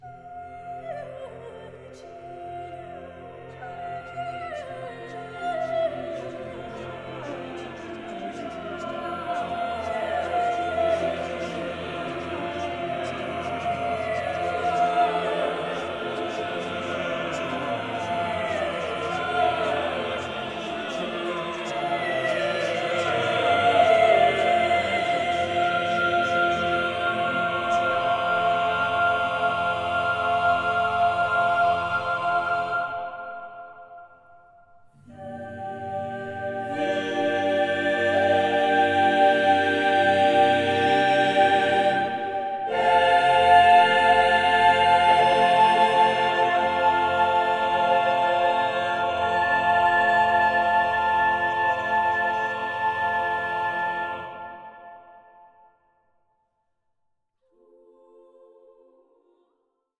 CHORAL DISC